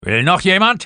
Sprecher: